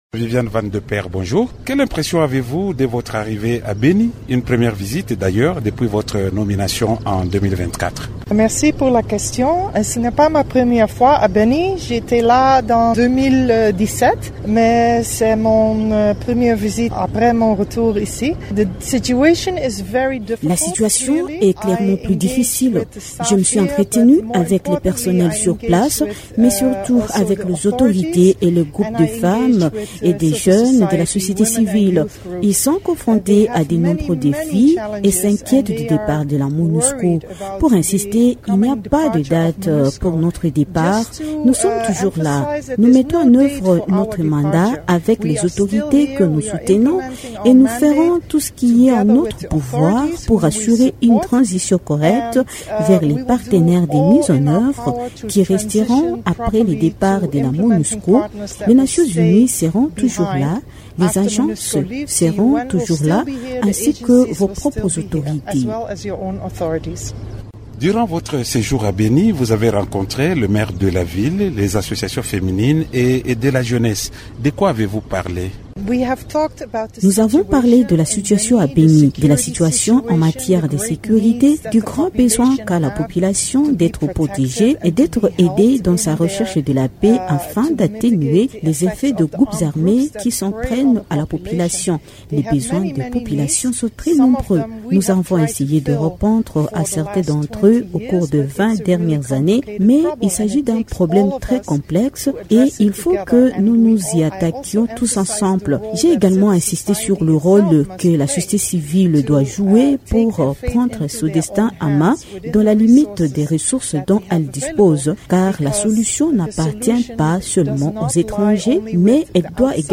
Invitée de Radio Okapi, elle insiste sur le renforcement de la protection de la population de cette partie du pays, en proie aux attaques des rebelles ADF.